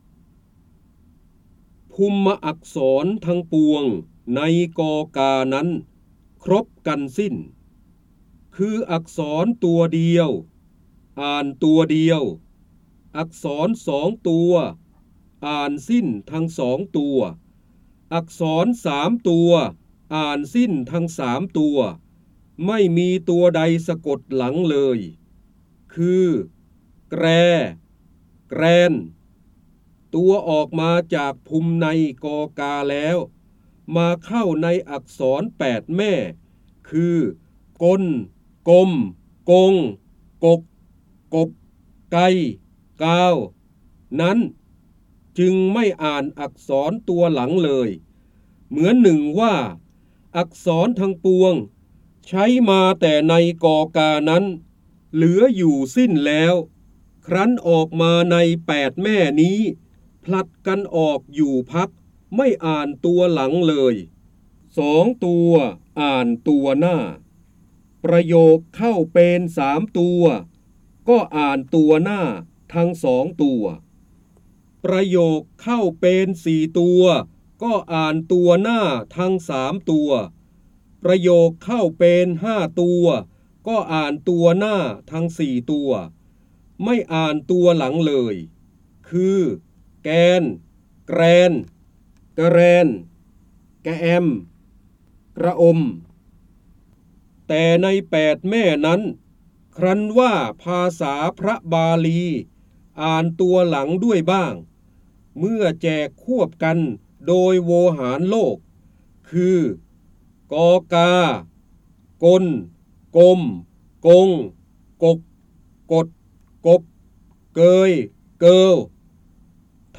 เสียงบรรยายจากหนังสือ จินดามณี (พระเจ้าบรมโกศ) ภุมอักษรทงงปวง ใน กกา นั้น
คำสำคัญ : การอ่านออกเสียง, พระโหราธิบดี, ร้อยกรอง, ร้อยแก้ว, จินดามณี, พระเจ้าบรมโกศ